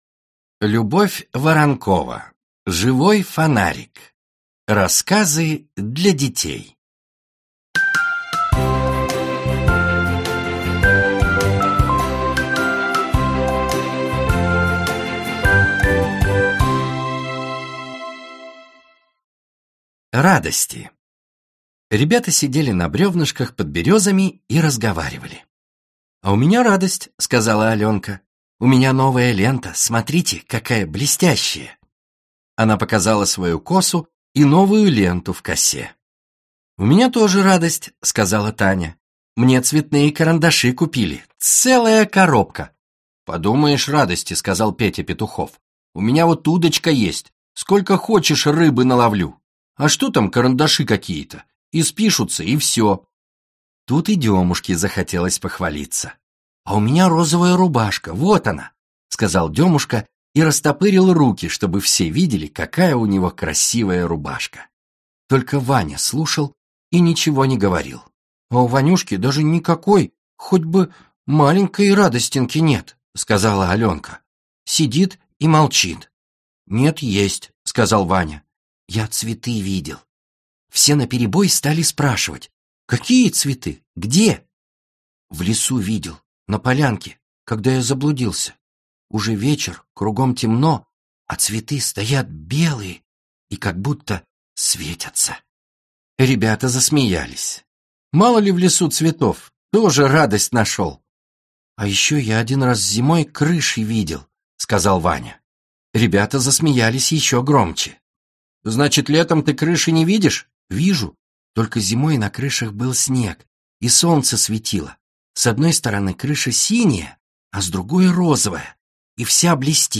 Аудиокнига Живой фонарик. Рассказы для детей | Библиотека аудиокниг